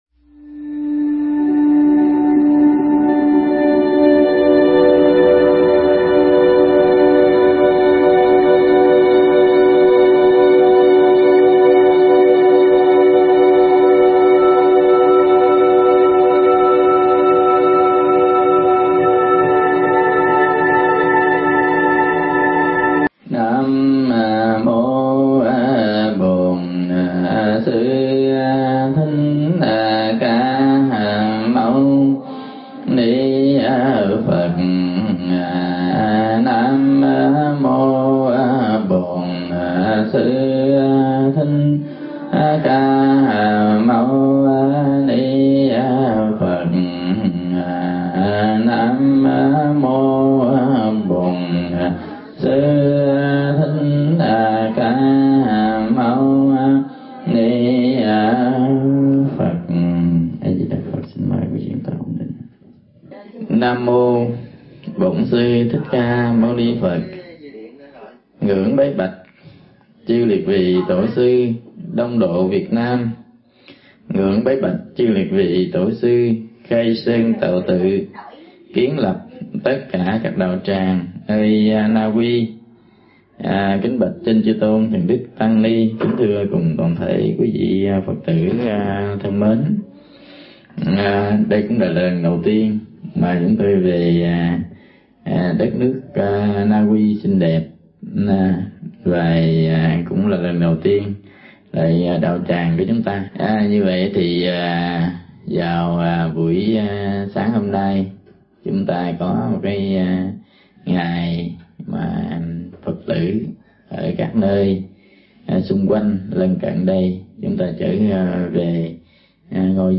thuyết giảng tại Nauy nhân chuyến hoằng pháp Châu Âu